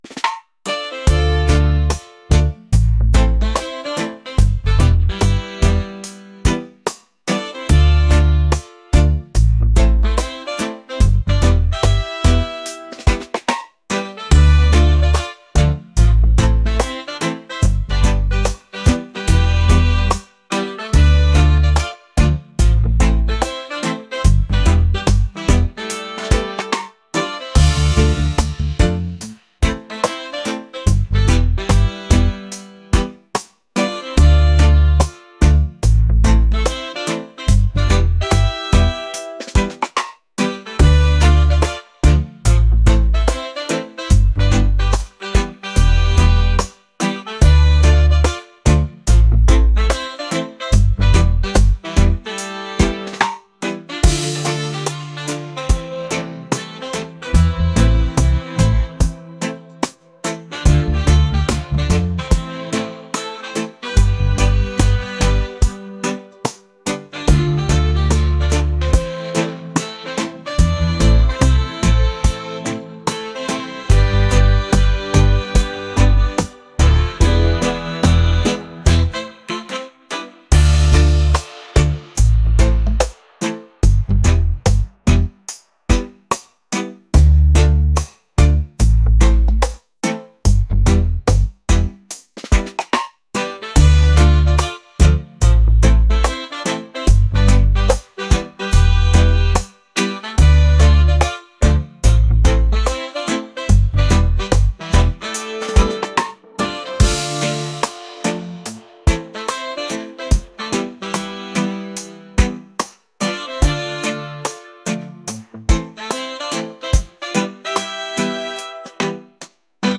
reggae | soul & rnb | lounge